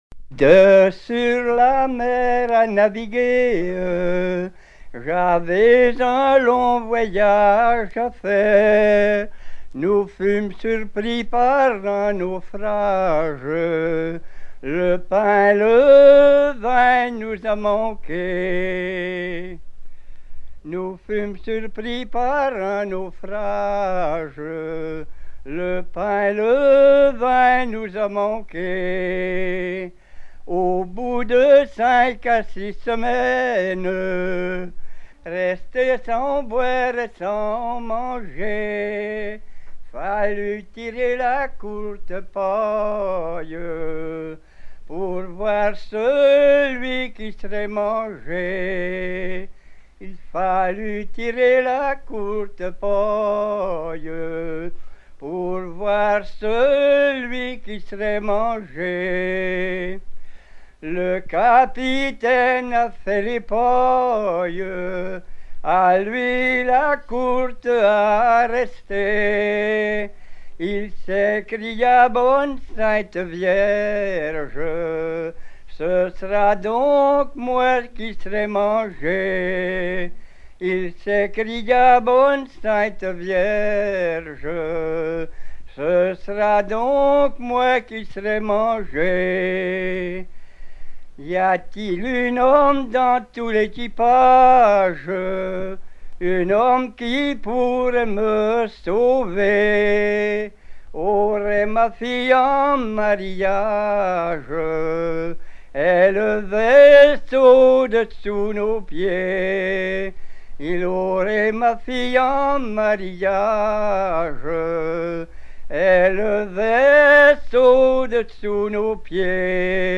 Chants de marins traditionnels